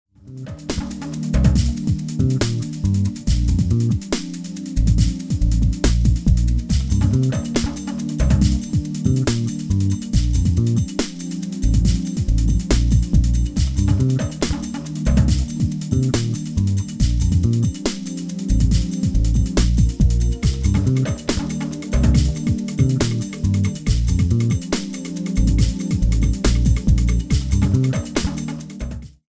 15 tracks of original percussion music
Nice consistent long tracks  - world music style